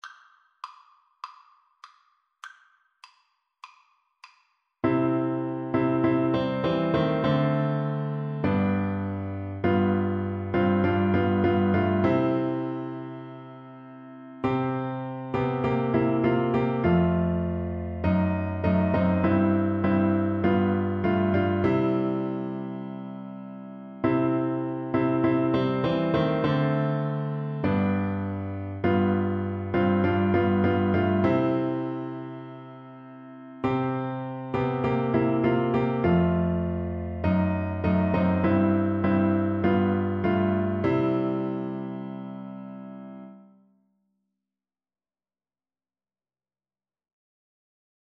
French Horn
C major (Sounding Pitch) G major (French Horn in F) (View more C major Music for French Horn )
Moderato
4/4 (View more 4/4 Music)
E4-E5
Traditional (View more Traditional French Horn Music)